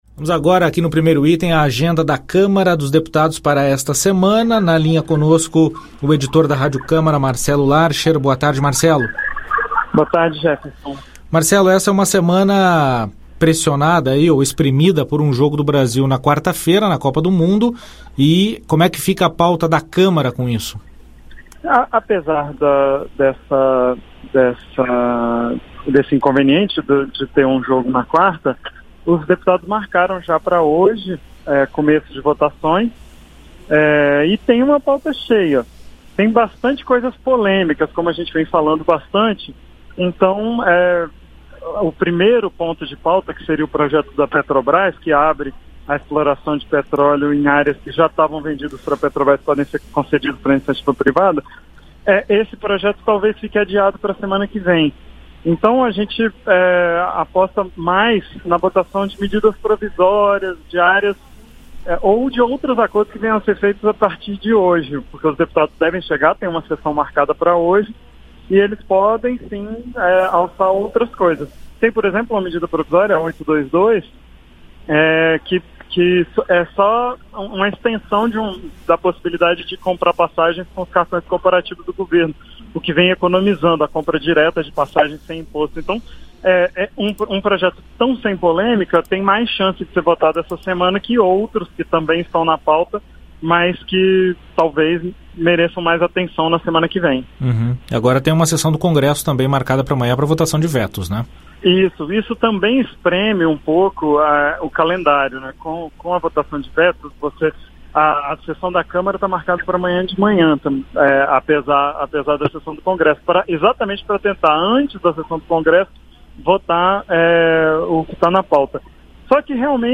Programa jornalístico que trata da agenda do Congresso Nacional, com entrevistas, comentários e reportagens sobre os principais assuntos em debate e em votação.